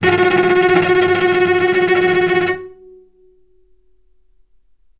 healing-soundscapes/Sound Banks/HSS_OP_Pack/Strings/cello/tremolo/vc_trm-F#4-mf.aif at c8d0b62ab1b5b9a05c3925d3efb84e49ca54a7b3
vc_trm-F#4-mf.aif